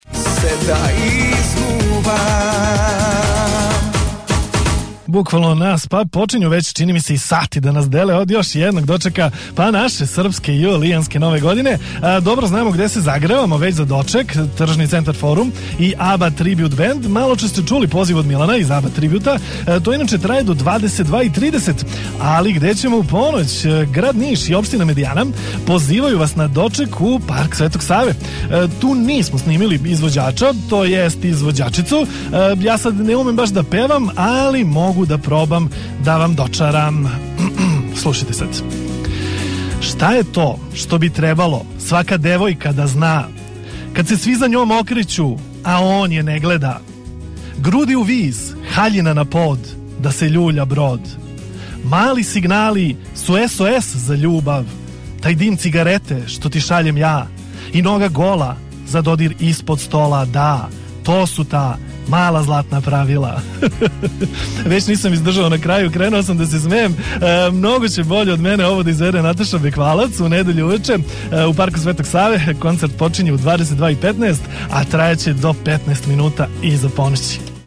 BANKER radio